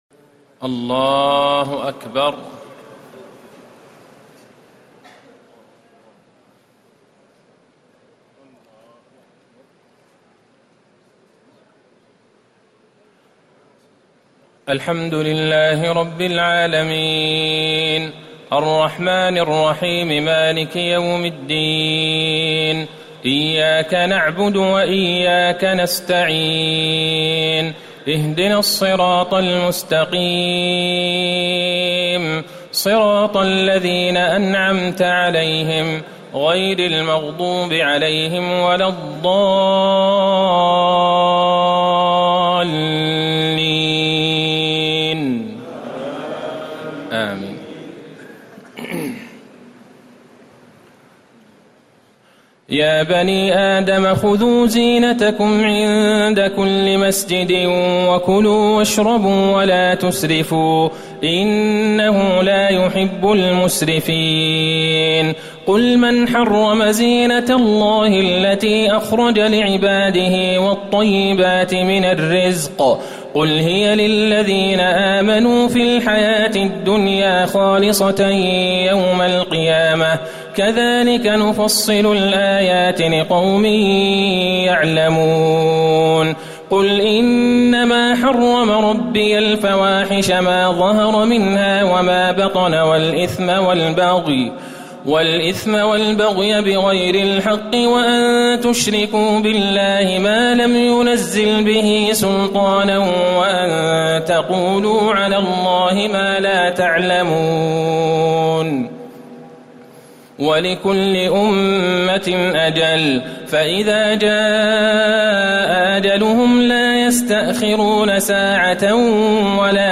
تهجد ليلة 28 رمضان 1439هـ من سورة الأعراف (31-87) Tahajjud 28 st night Ramadan 1439H from Surah Al-A’raf > تراويح الحرم النبوي عام 1439 🕌 > التراويح - تلاوات الحرمين